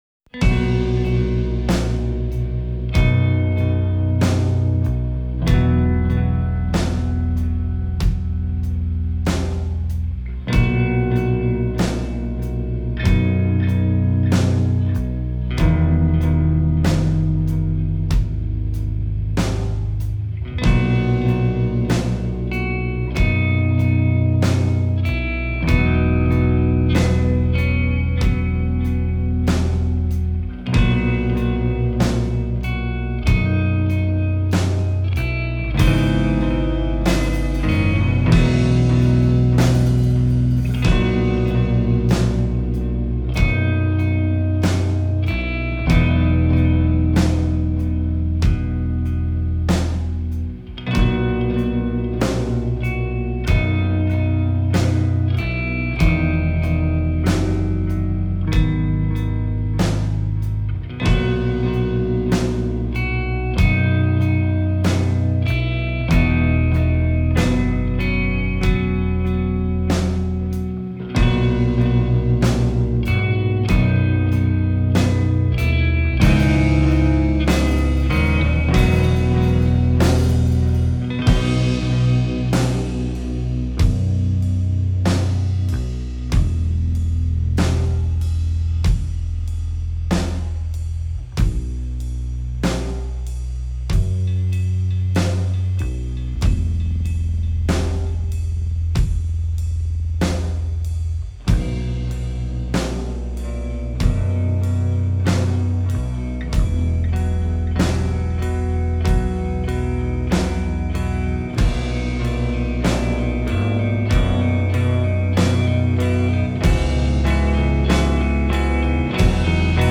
metal
sludge/doom